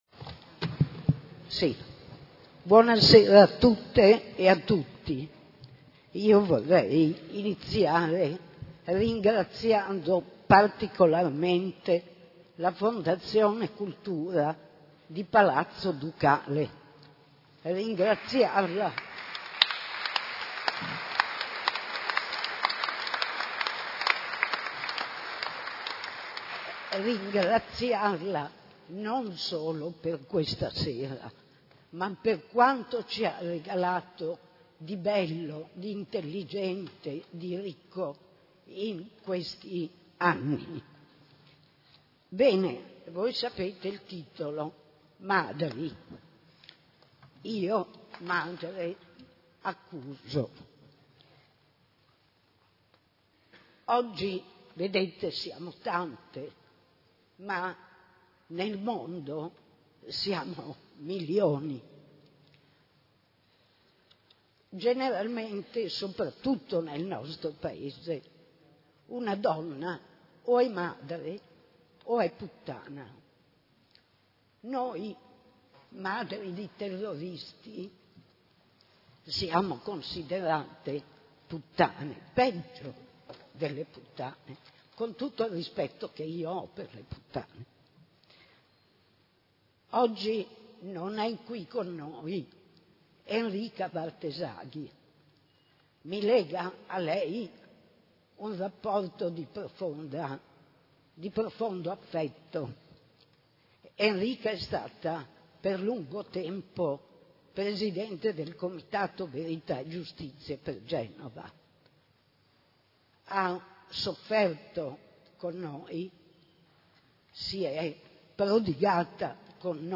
Il Convegno a Palazzo Ducale – Luglio 2017